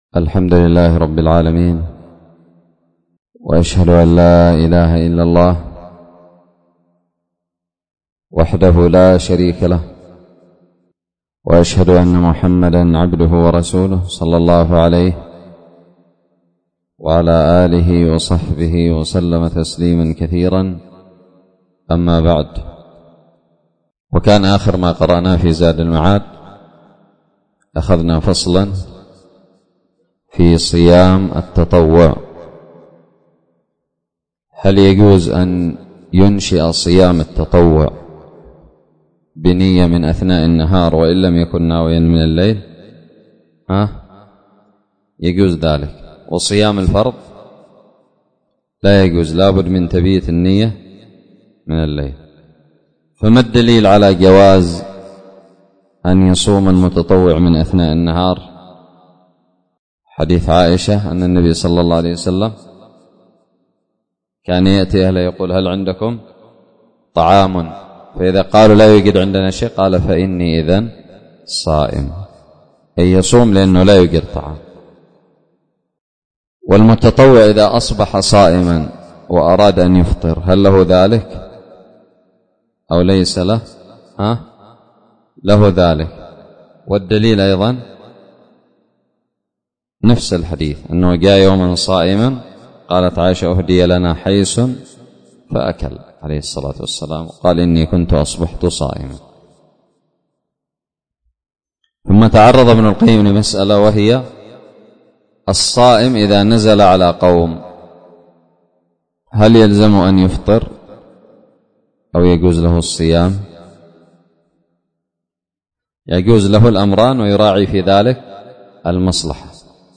الدرس التاسع عشر من التعليق على فصل هدي النبي صلى الله عليه وسلم في الصوم من زاد المعاد
ألقيت بدار الحديث السلفية للعلوم الشرعية بالضالع